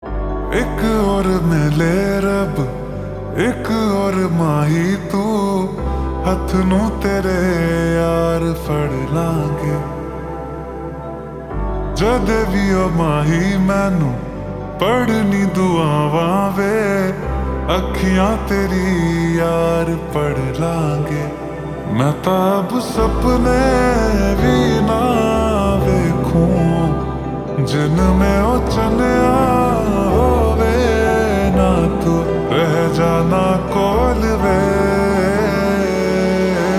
Slowed + Reverb